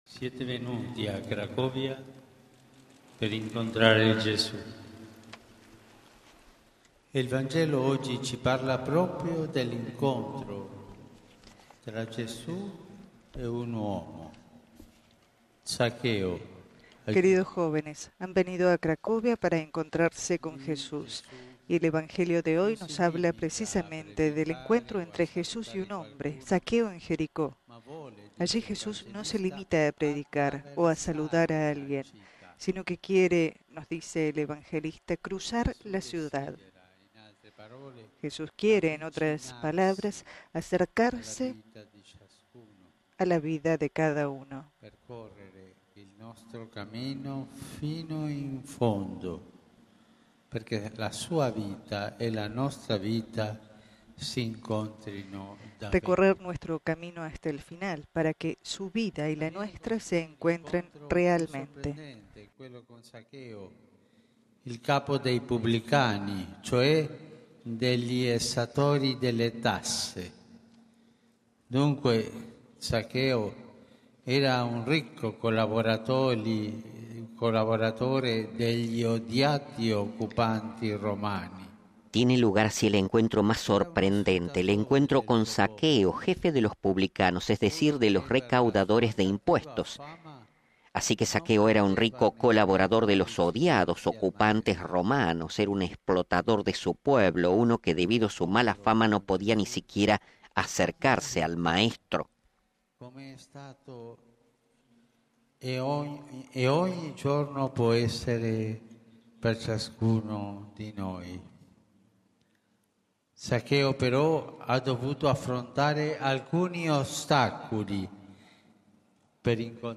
(RV).- En el inmenso Campus Misericordiae de Cracovia, lugar de la vigilia y, la mañana de este domingo, de la misa final de la Jornada Mundial de la Juventud 2016, resonaron con energía las palabras del Papa Francisco, invitando a los chicos y chicas del mundo a salir al encuentro de Jesús.
Precisamente en su homilía el Santo Padre citó el Evangelio del día que narra el encuentro de Jesús con Zaqueo.